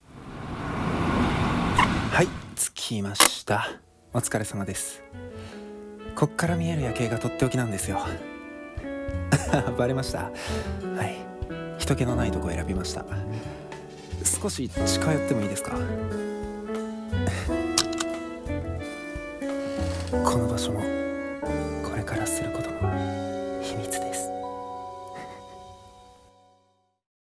秘密です 【シチュエーション 1人声劇】